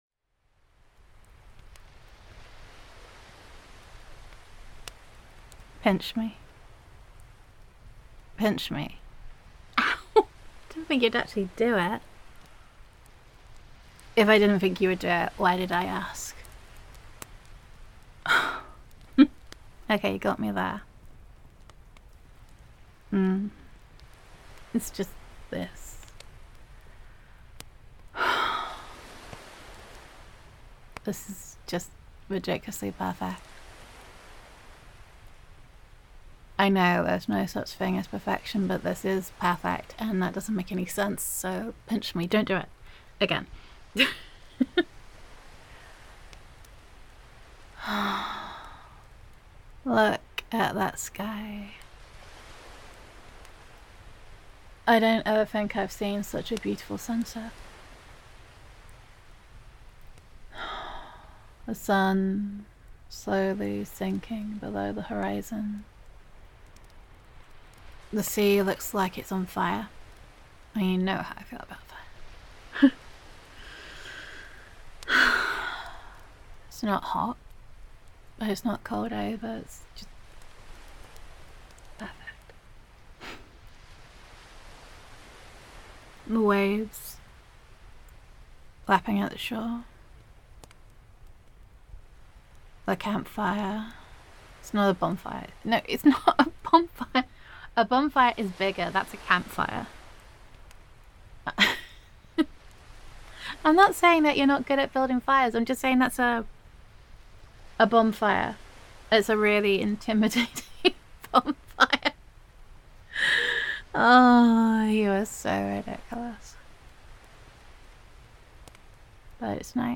[F4A] Sea on Fire [Camp Fire on the Beach]
[Ocean Waves][Girlfriend Roleplay]